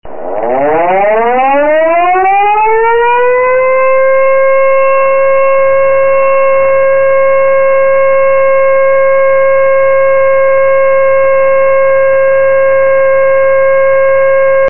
ELECTROSIRENA GRAN POTENCIA OMNIDIRECCIONAL MOTOR TRIFÁSICO
De elevada potencia acústica
4KW - 131dB